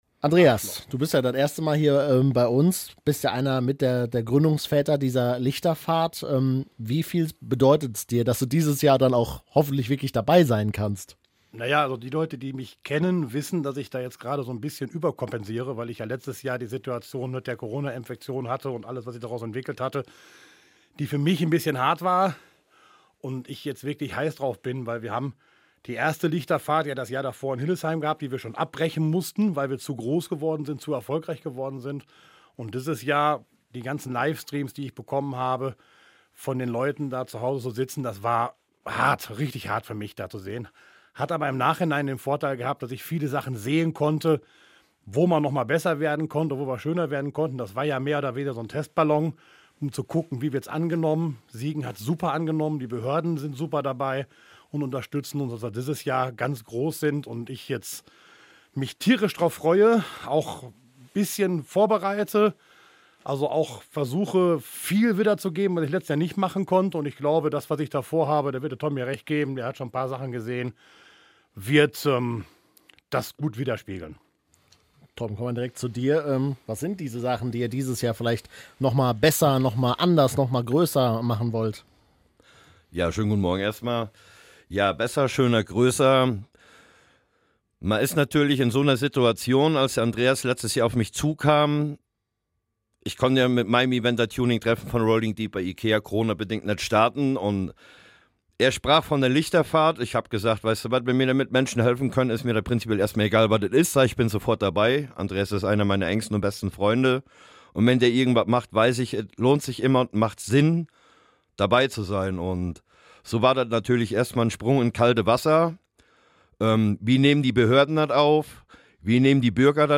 Siegener Lichterfahrt - Interview uncut download play_circle Abspielen download Anzeige